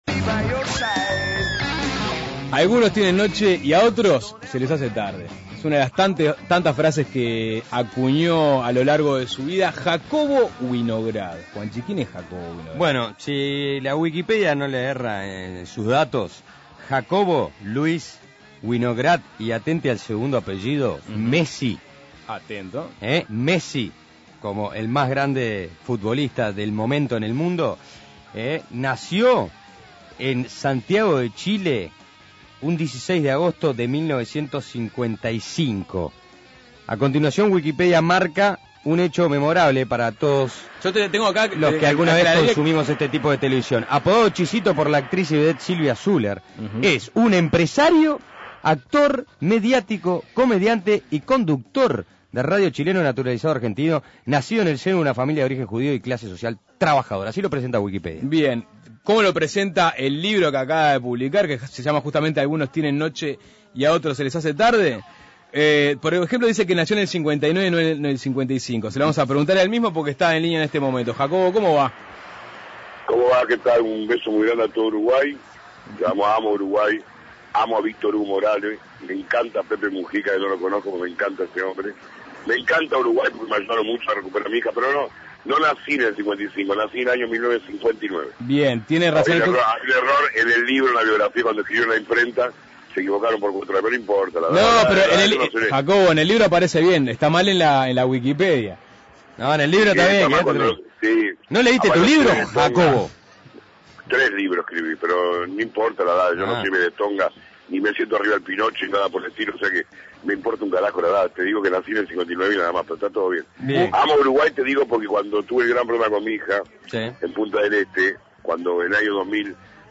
Una entrevista que dio para hablar de todo: la noche, la televisión, el juego, la política y la responsabilidad periodística.